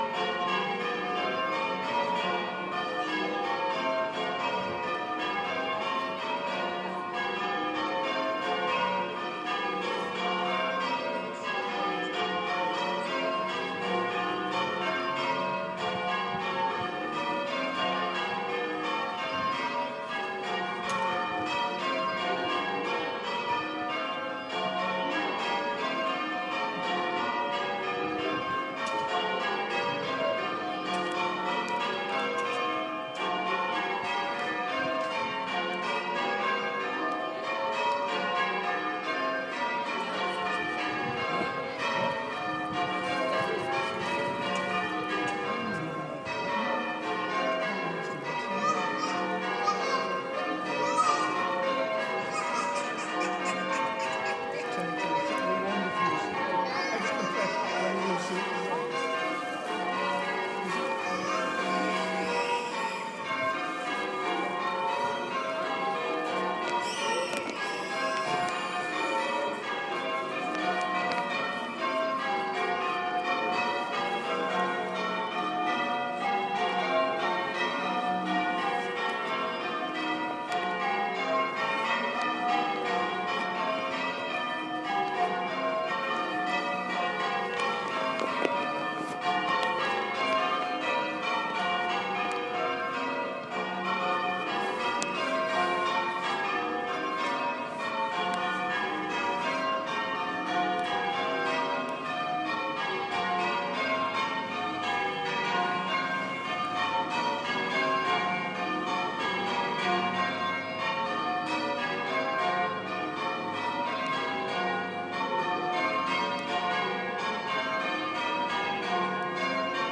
St Swithin's Bells 2. Not sure the baby's a fan! I'''m not a roadsweeper fan either!